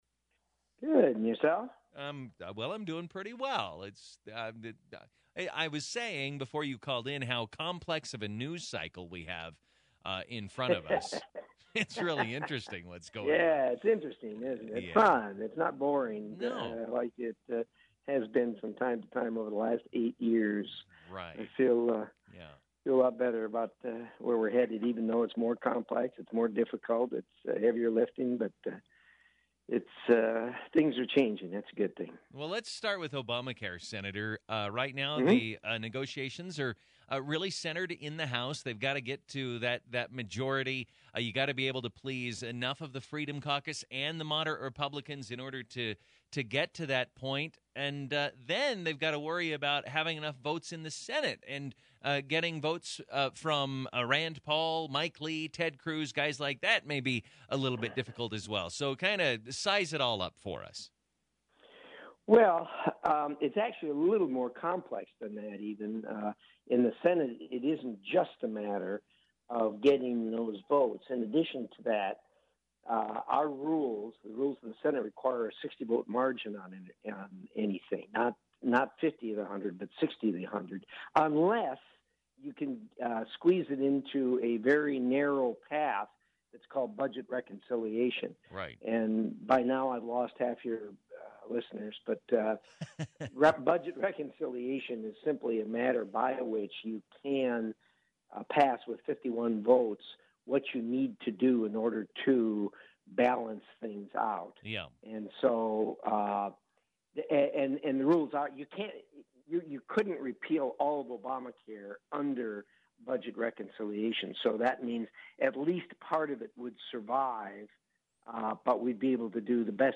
KID Radio Interview March 23, 2017